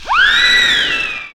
SFX PIPE W02.wav